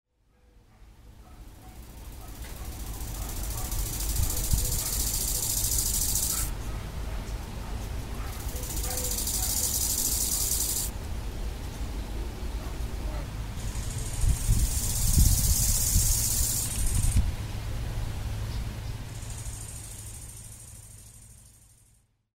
ko2_konik_polny.mp3